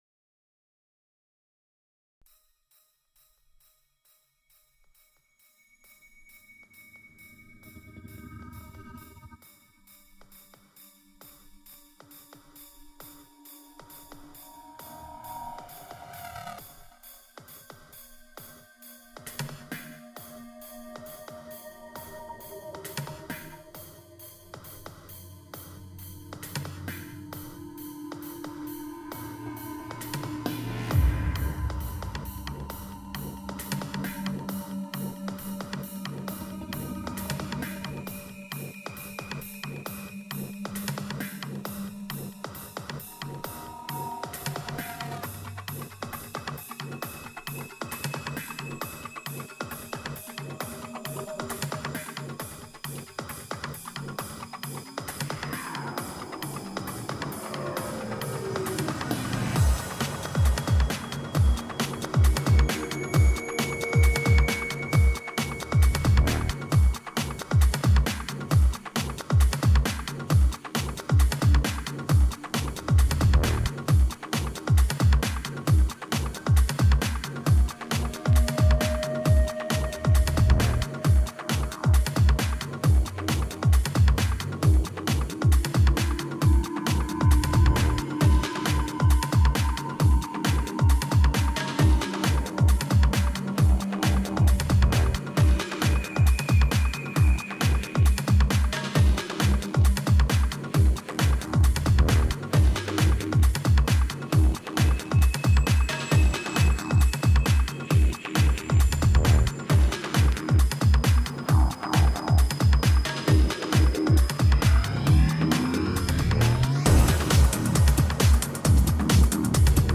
A few years back, he set up an outdoor DJ station on the Panorama property, and fueled by a cooler full of Tecate, laid down a number of creative mixes to pay tribute to the land of the notorious Below the Chateau shindig. This summer of 2008 mixapalooza generated some interesting soundscapes that wandered widely into not-so-normal musical genres.